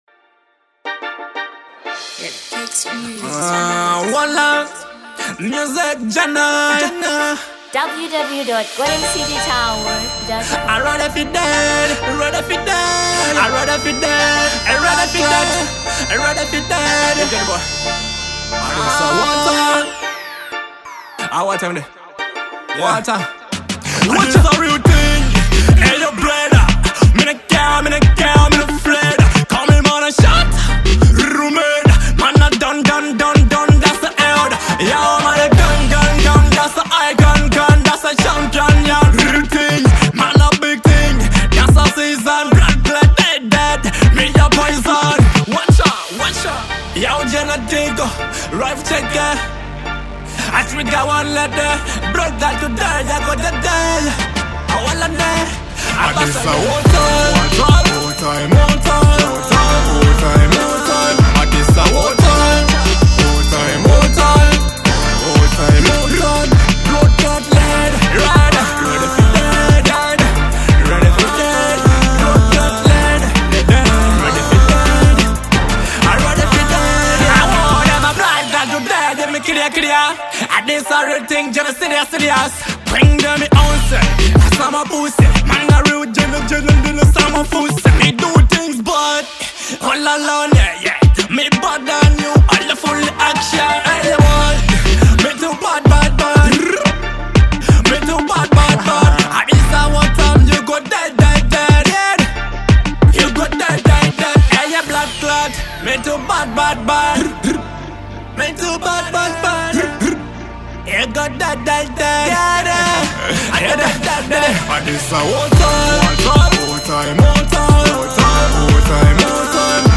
2. Dancehall